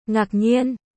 ベトナム語発音
ベトナム語で「驚いた」という表現その④：ngạc nhiên（ガックニエン）
この言葉は漢越語と呼ばれる漢字由来のベトナム語であり、日本語の愕然という言葉と発音が似ているのが特徴です。